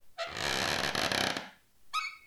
creak.mp3